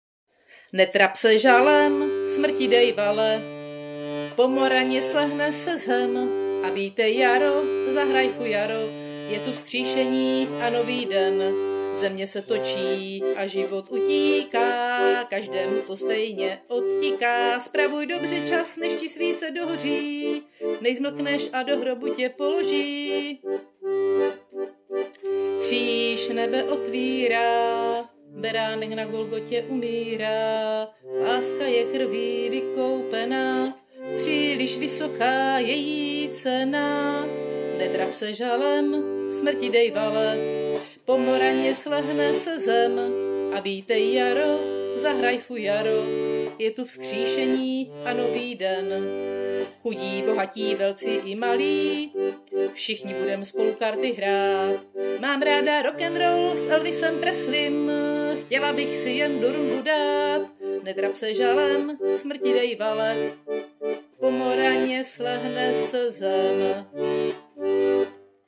Anotace: pisnicka